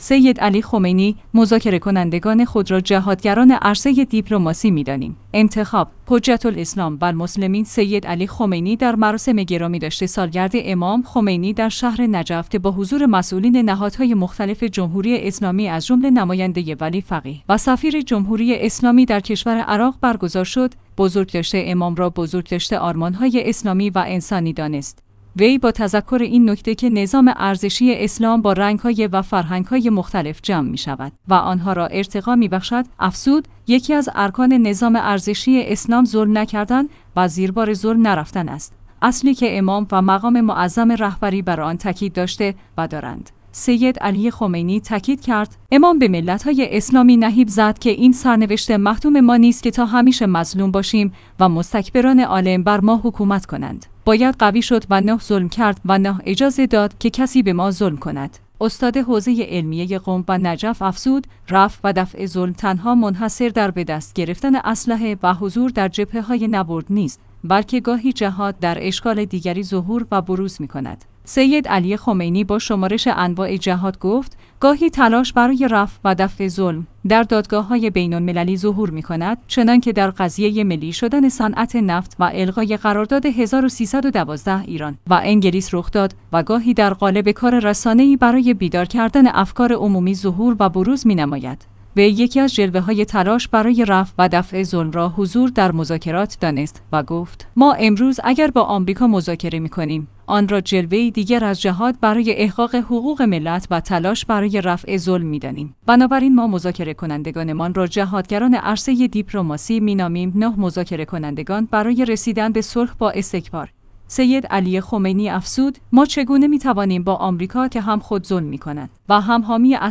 انتخاب/ حجت الاسلام و المسلمین سید علی خمینی در مراسم گرامیداشت سالگرد امام خمینی در شهر نجف که با حضور مسئولین نهادهای مختلف جمهوری اسلامی از جمله نماینده ی ولی فقیه و سفیر جمهوری اسلامی در کشور عراق برگزار شد، بزرگداشت امام را بزرگداشت آرمانهای اسلامی و انسانی دانست.